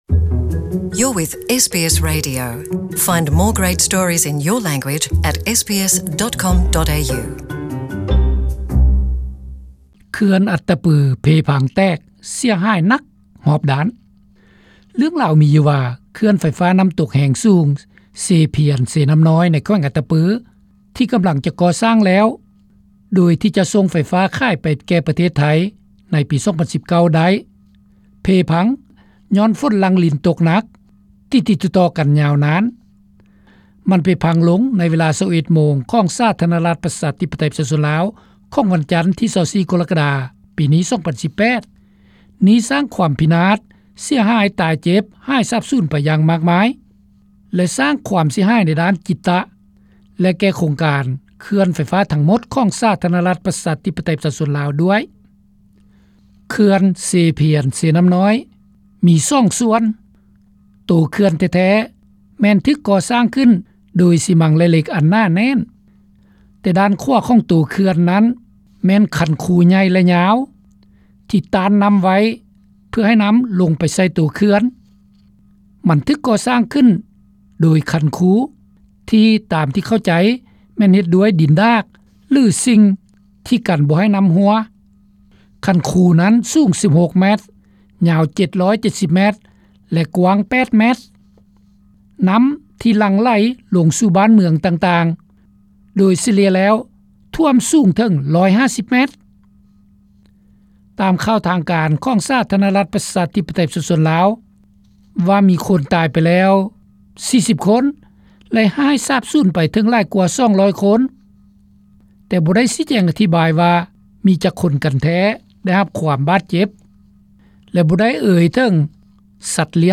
Xe Pian-Xe Namnoy hydropower dam in Attapeu province of Laos collapsed causing huge damages to all lives and environment. It is claimed heavy rains are the main cause of the tragedy. The interviews below will tell you what have been happening Exclusive interviews of: Mr. “X”, a field reporter in…